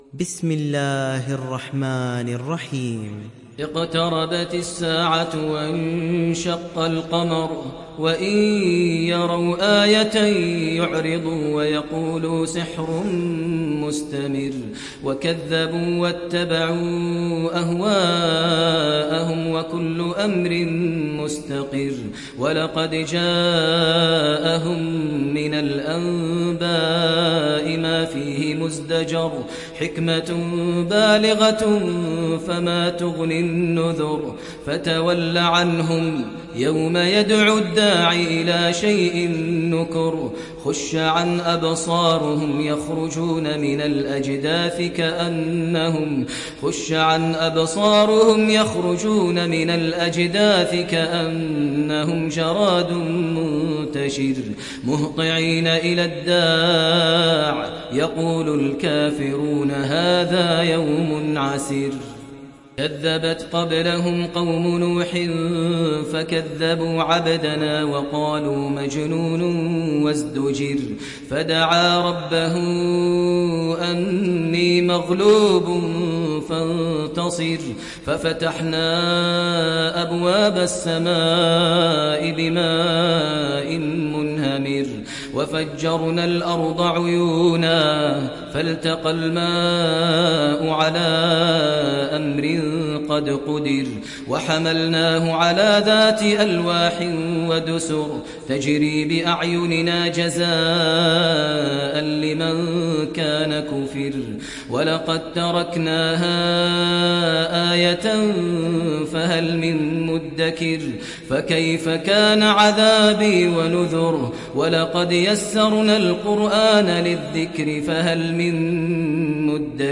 Kamer Suresi İndir mp3 Maher Al Muaiqly Riwayat Hafs an Asim, Kurani indirin ve mp3 tam doğrudan bağlantılar dinle